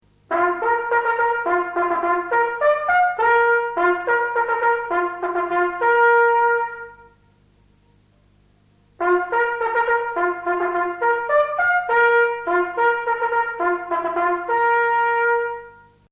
Die Posthorn-Signale werden immer zweimal gespielt.
Posthorn-Signal: Ankunft einer Dienstpost (MP3)